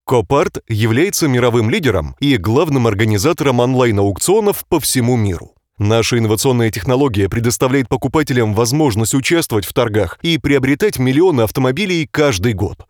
Bilingual Russian and Ukrainian Voice Over Talent with own studio
Sprechprobe: Industrie (Muttersprache):